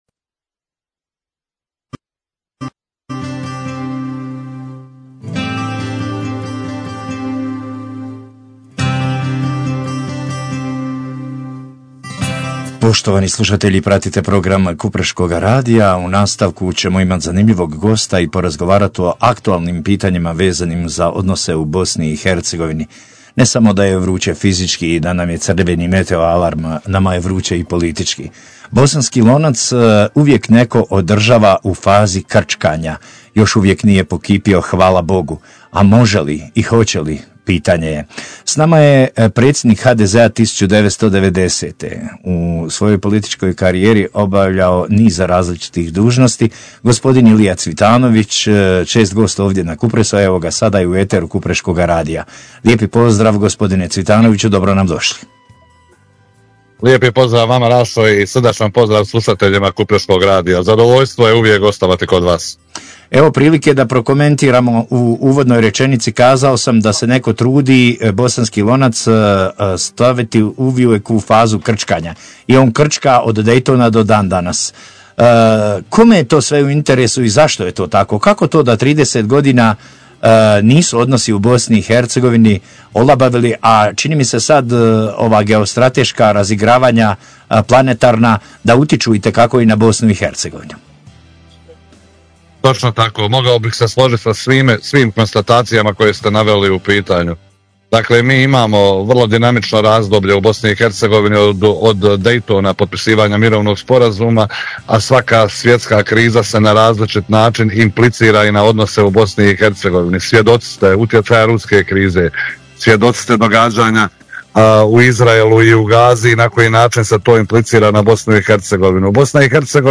Ilija Cvitanović HDZ 1990 o aktualnim temama govorio za Kupreški radio